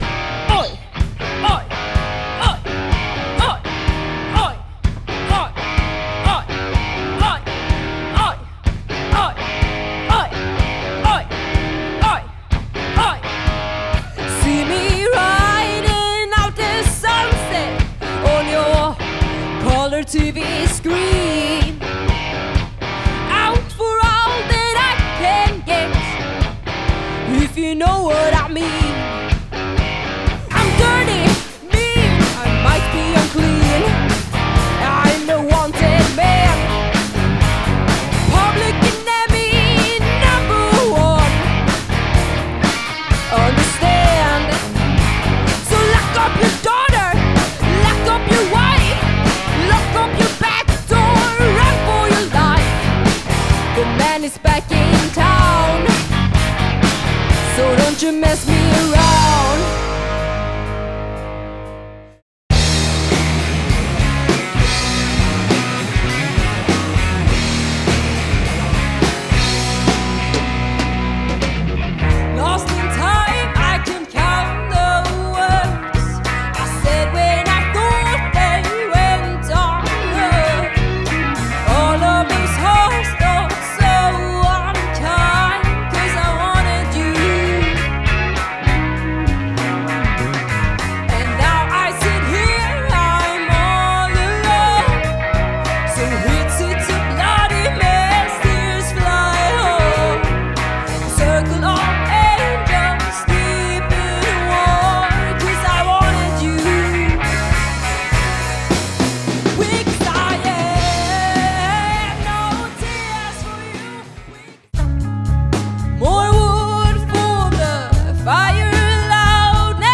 Partyrockande Coverband som garanterar fullt ös.